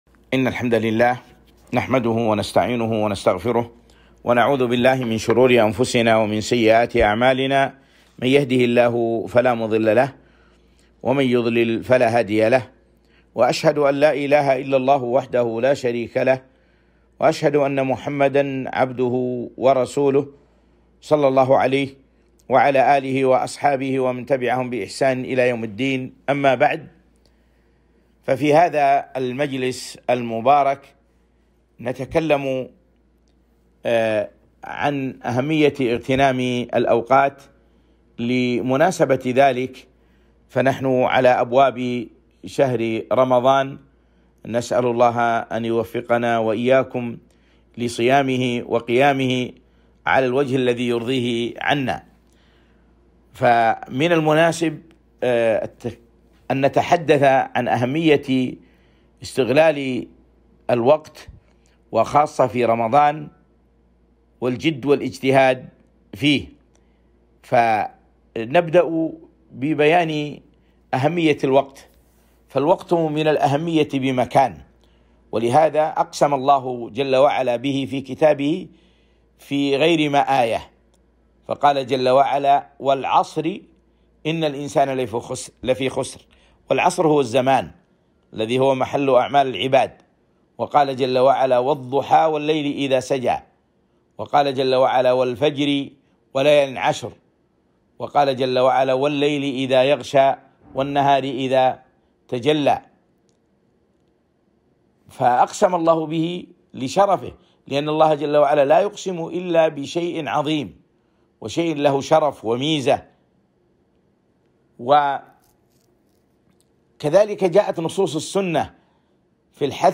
محاضرة - اغتنام الأوقات في رمضان - دروس الكويت